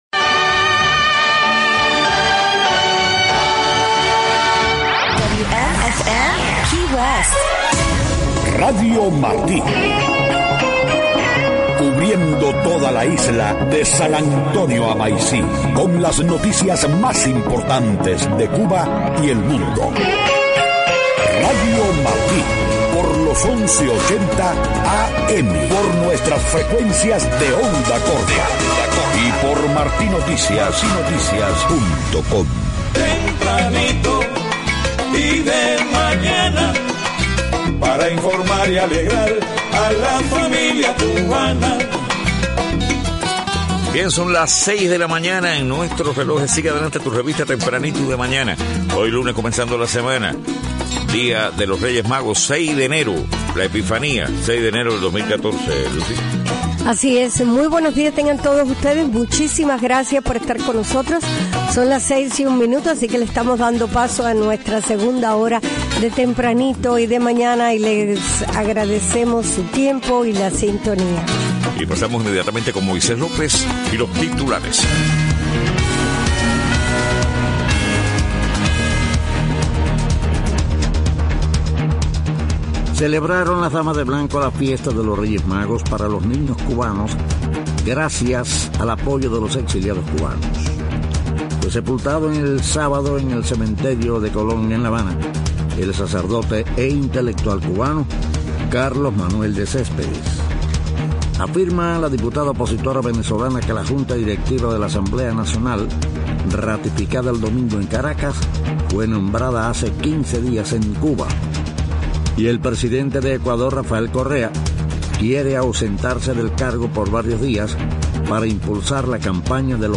6:00 a.m. Noticias: Negocios en Cuba operados por particulares pagan mejores salarios a sus empleados que los estatales.